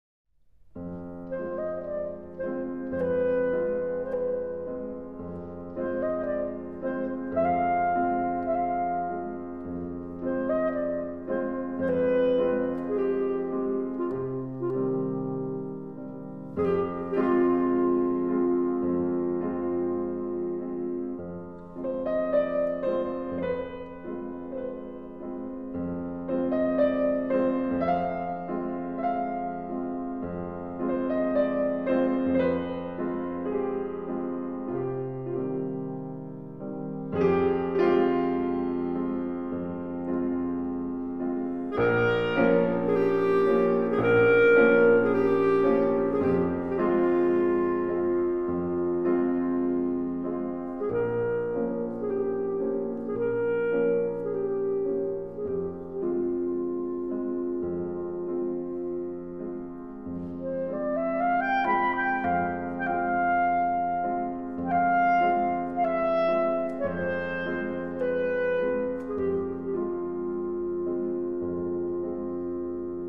Arranging Symphonic, Vocal, and Piano Works for performance on Carillon
This translated to a contemplative musical style.
I left the melody in the middle.